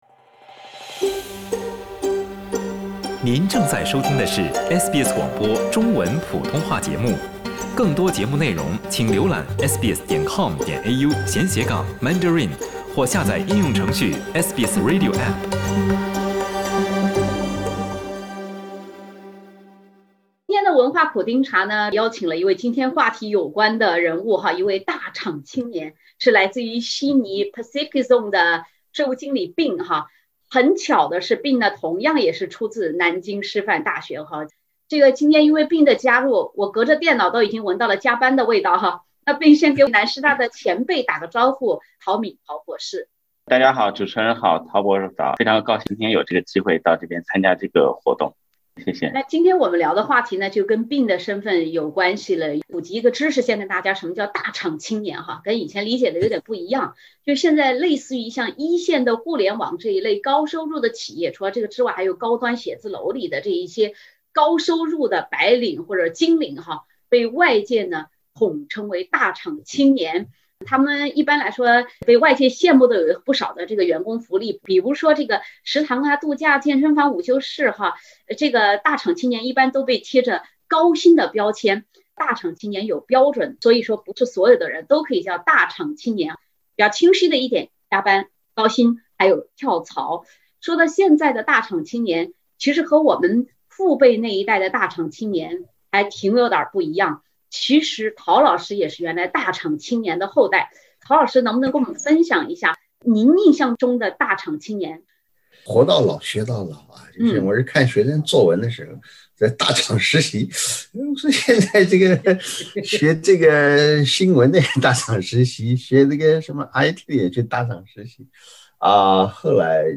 （点击封面图片，收听有趣对话）